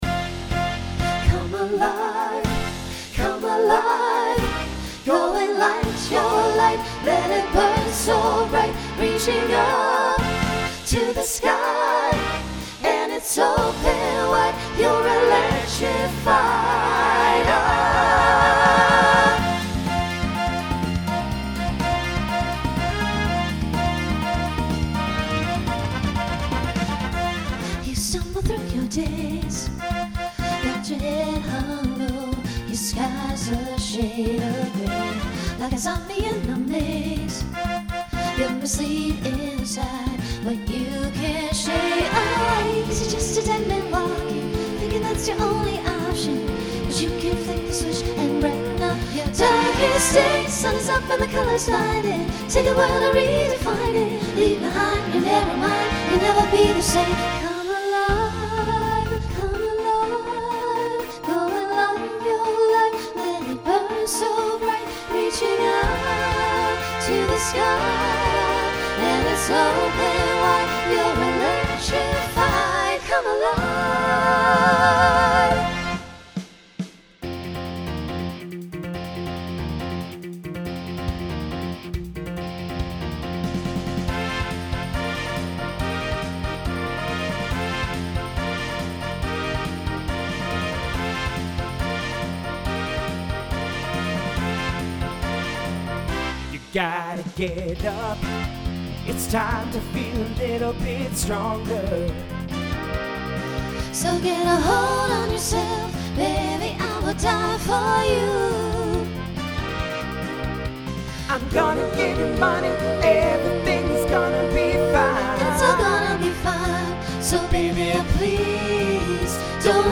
Includes an SSA trio to accommodate a costume change.
Pop/Dance
Voicing Mixed